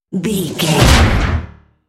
Dramatic creature hit trailer
Sound Effects
Atonal
heavy
intense
dark
aggressive
hits